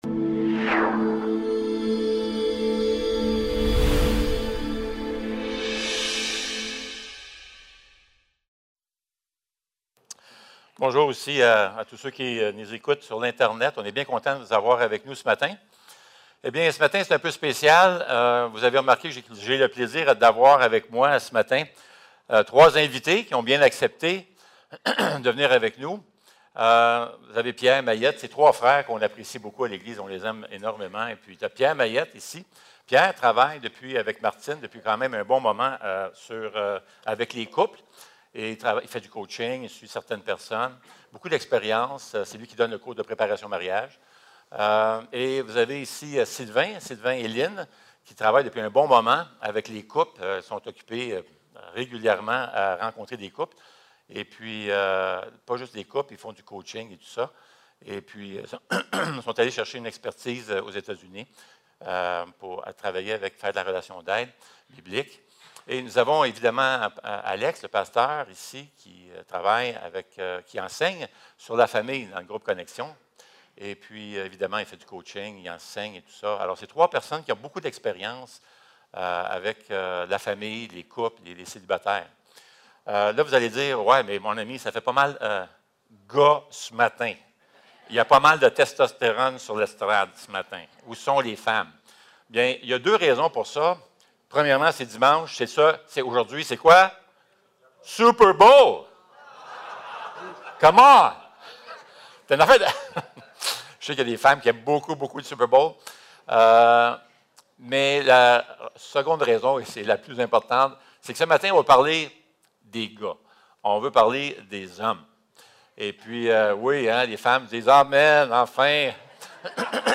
La Maison de Dieu (8) - Les hommes et l’Évangile - Panel de discussions < église le Sentier | Jésus t'aime!